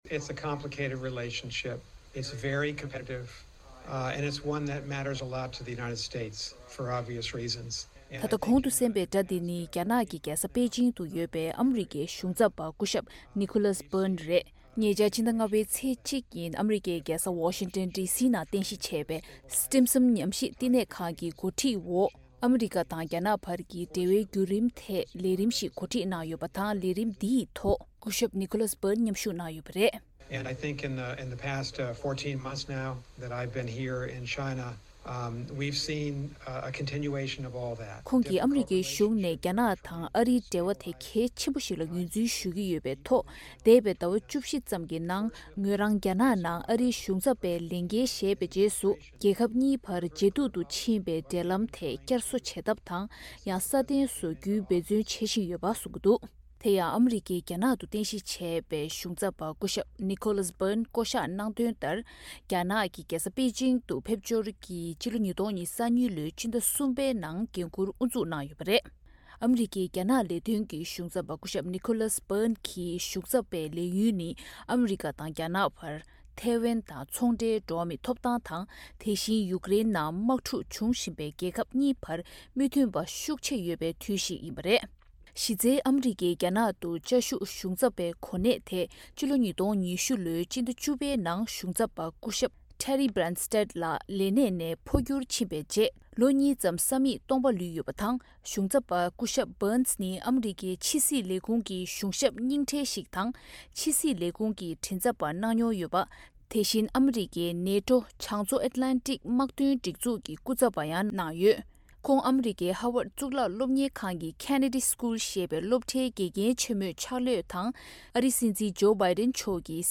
སྒྲ་ལྡན་གསར་འགྱུར། སྒྲ་ཕབ་ལེན།
གསར་འགོད་པ།